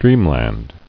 [dream·land]